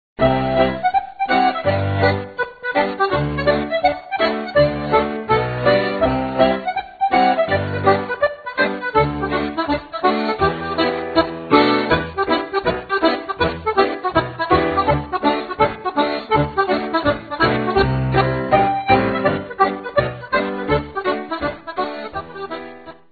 fiddle
piano
melodeon
is a dotted hornpipe in common time